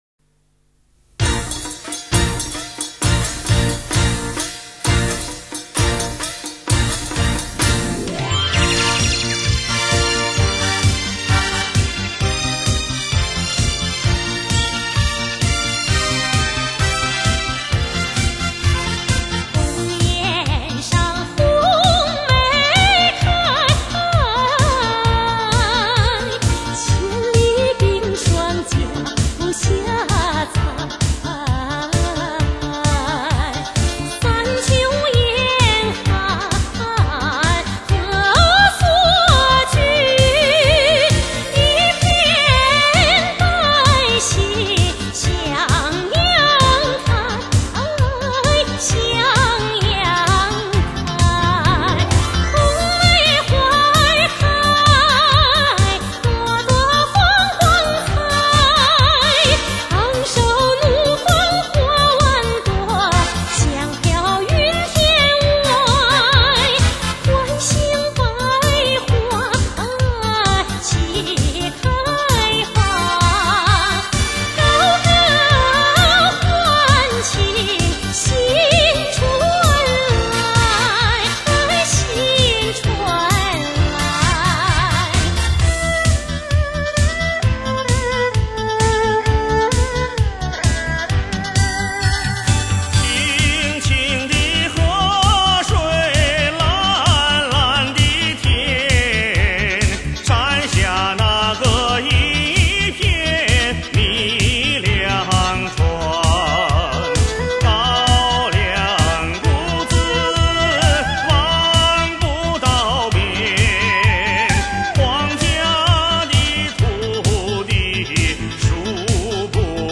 经典民歌优秀电影插曲